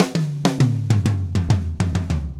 Drumset Fill 08.wav